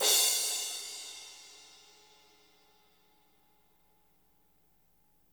-CRASH 2  -R.wav